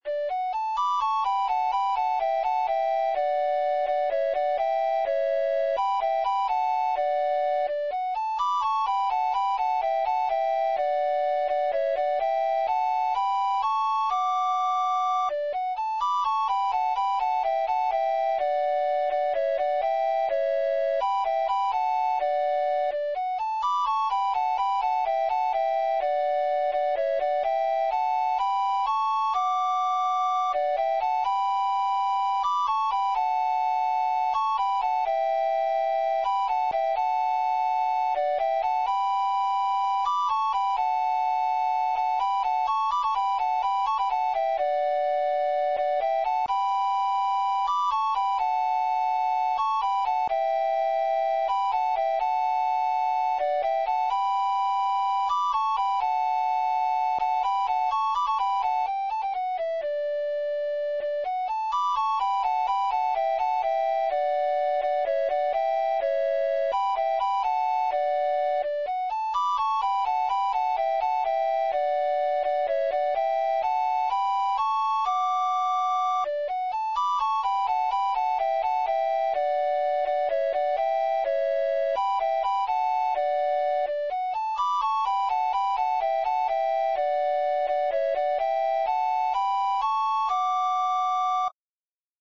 Pasodobres – Páxina 2 – Pezas para Gaita Galega
Pasodobres
Seran uns arquivos mp3. moi sinxelos xerados a partires dun midi, máis para darse unha idea de como soa o tema agardo que sirvan.
Dúo